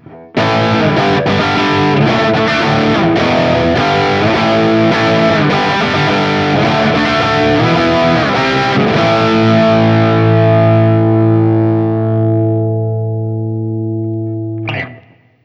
This is an aggressive sounding guitar that’s a bit dark for my tastes, a fact that I attribute to the Guild XR7 pickups and the mahogany body.
Marshal 100W Treble
As usual, for these recordings I used my normal Axe-FX II XL+ setup through the QSC K12 speaker recorded direct into my Macbook Pro using Audacity.
That last recording was done on the bridge pickup with the volume rolled of just a bit because the pickups are too hot for my tastes.
Guild-X79-M100WT-WannaRock.wav